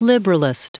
Prononciation du mot liberalist en anglais (fichier audio)
Prononciation du mot : liberalist